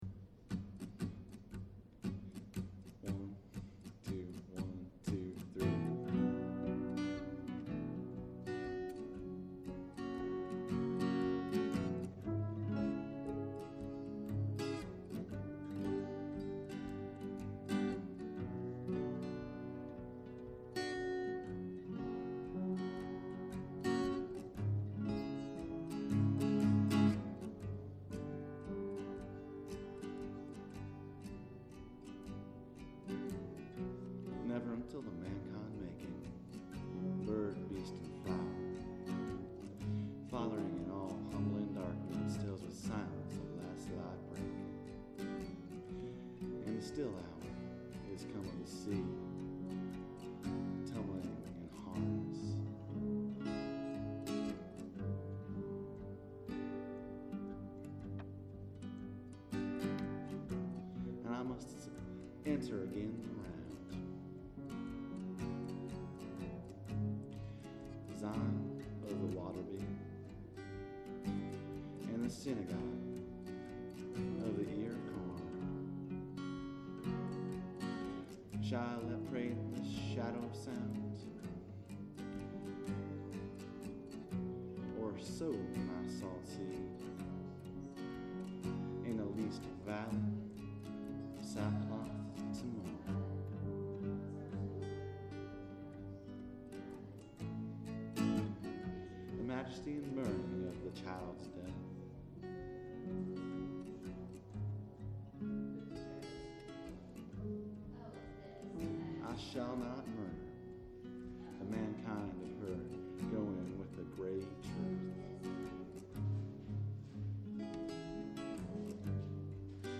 The text is quiet on a lot of these, which I think may be due to the acoustics of the Sherwood Room.
Recorded June 12, 2010, in the Sherwood Room, Levering Hall, JHU Homewood campus
vox, soprano sax
vox, piano, percussion